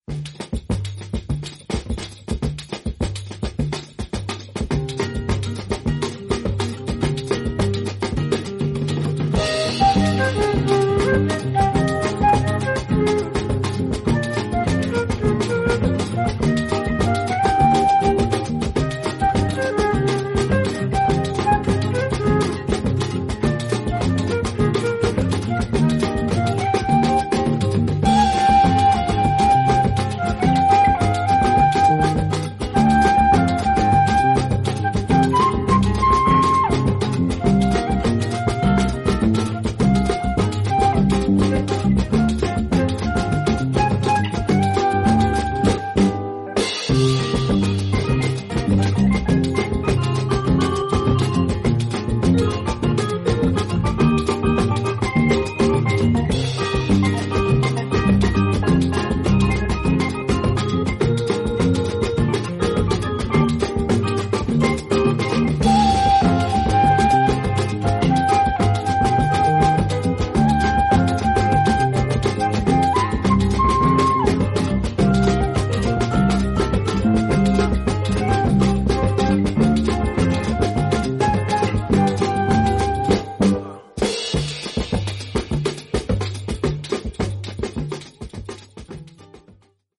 こちらのアルバムは本名でのスタジオセッション